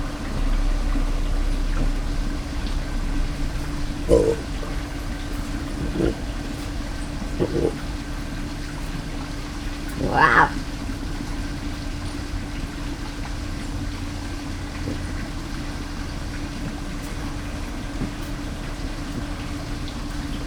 Directory Listing of //allathangok/jaszberenyizoo2016_professzionalis/mocsarihiuz/